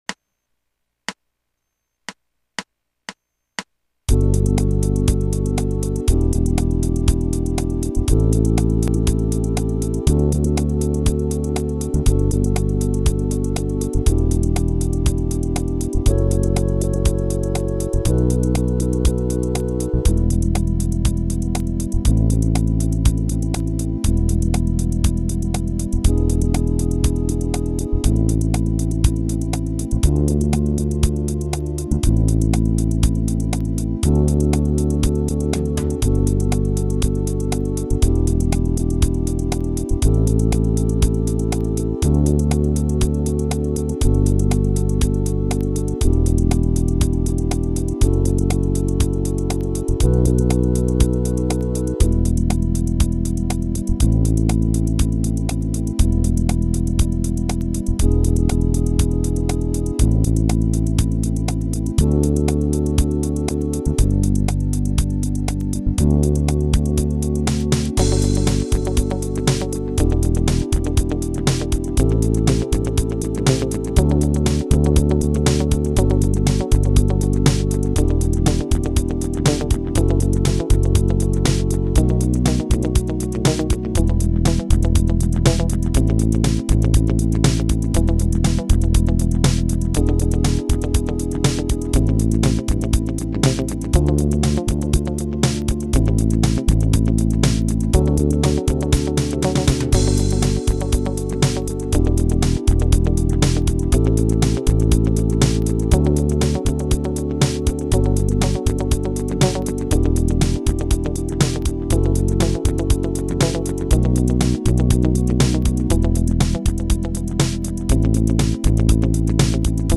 1. The first track is in a pop style: